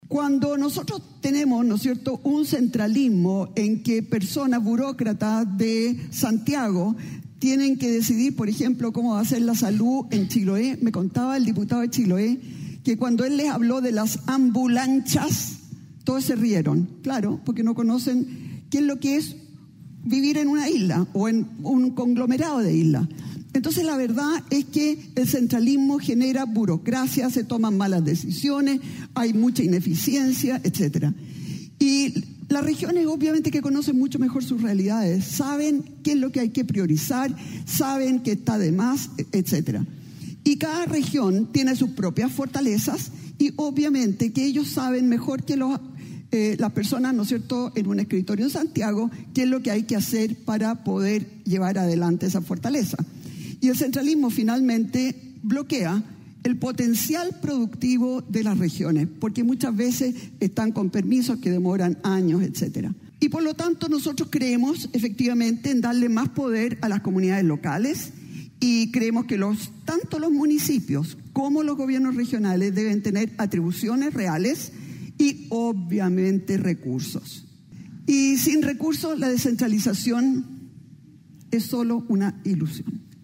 “Descentralización para avanzar” fue el lema de la Cumbre de las Regiones 2025, realizada este lunes en el Teatro Biobío y organizada conjuntamente por Corbiobío, el Gobierno Regional, Desarrolla Biobío y la Asociación de Gobernadores y Gobernadoras Regionales de Chile (Agorechi).